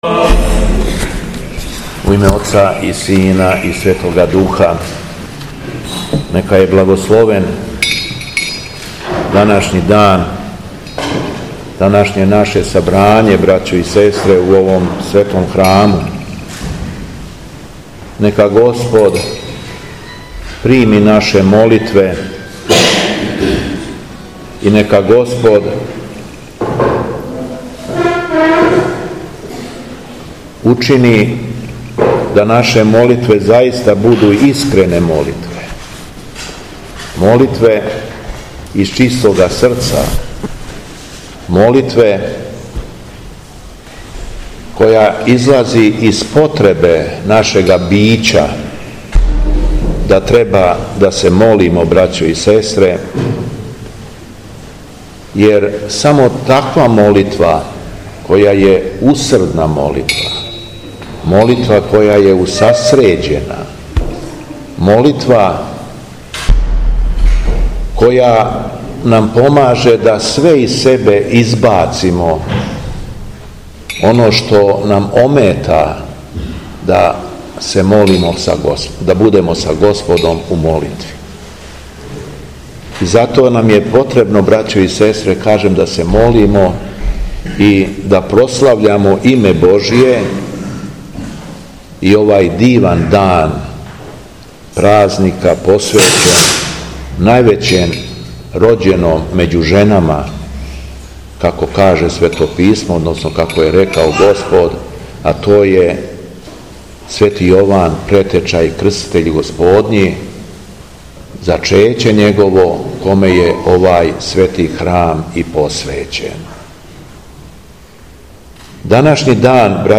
ХРАМОВНА СЛАВА У ЈОВАНОВЦУ - Епархија Шумадијска
У храму Зачећа Светог Јована Крститеља, у петак 6. октобра 2023. године, на храмовну славу, служио је Свету архијерејску литургију Његово Преосвештенство г. г. Јован, Епископ шумадијски.
Беседа Његовог Преосвештенства Епископа шумадијског г. Јована